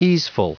Prononciation du mot easeful en anglais (fichier audio)
Prononciation du mot : easeful